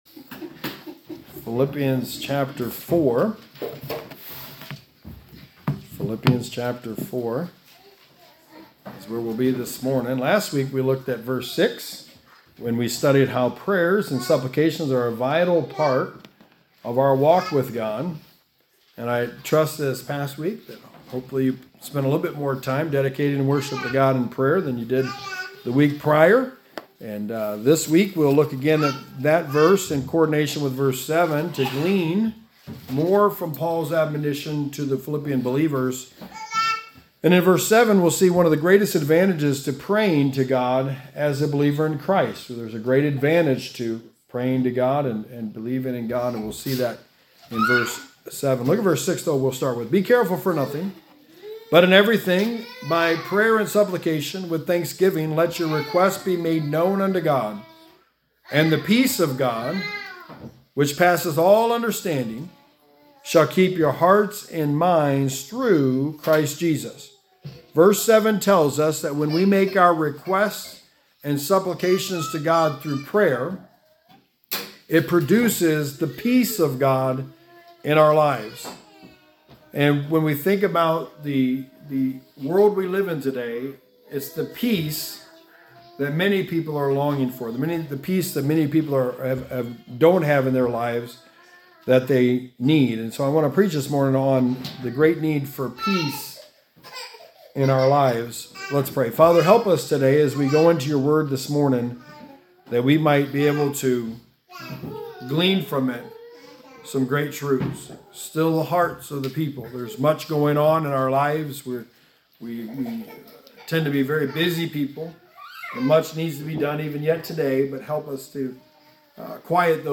Passage: Philippians 4:7 Service Type: Sunday Morning